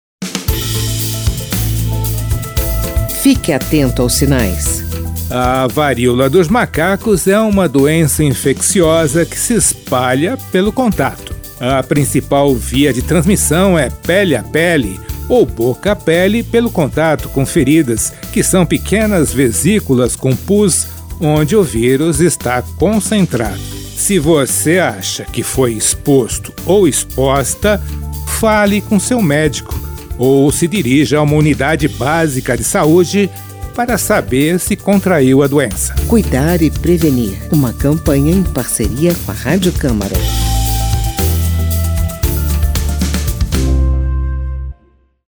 Texto e locução